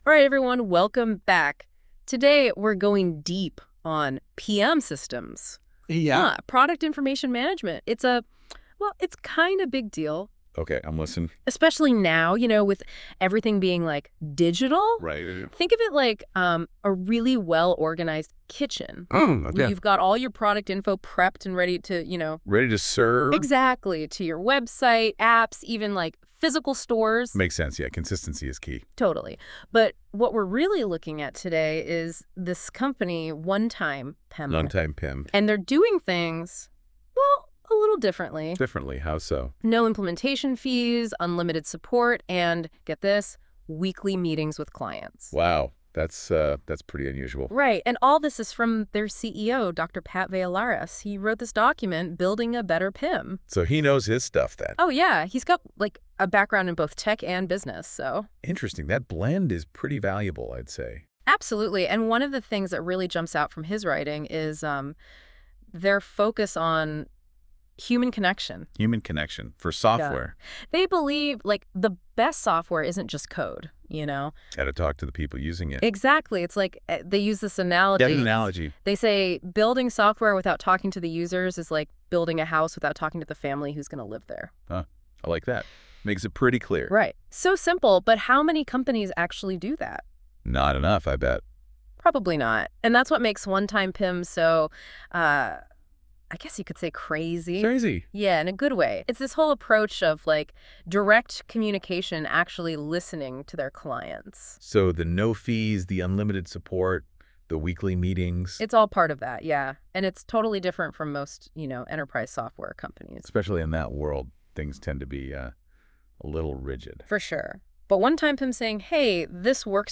Click here to listen to a Podcast discussing this Blog made with AI!